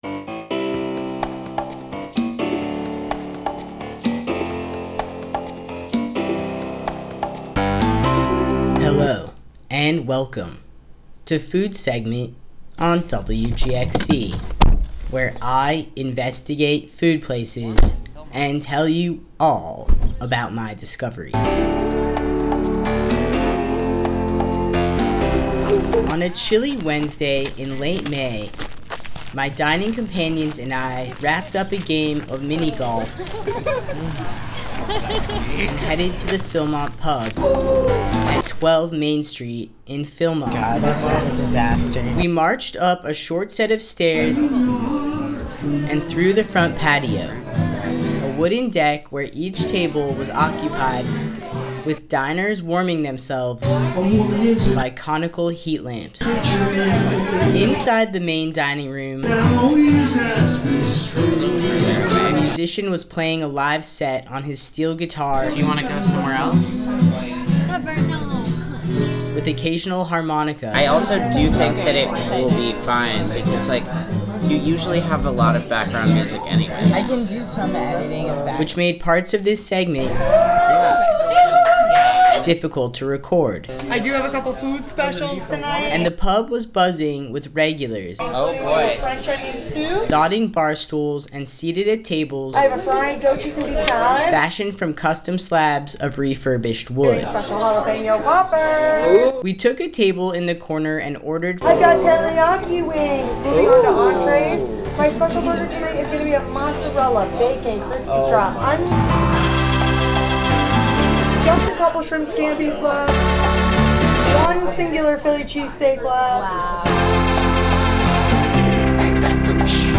At The Philmont Pub ( 12 Main St. Philmont) Food Segment participants weigh in on a plethora of bar menu classics and dessert, after a tense debate about what to order.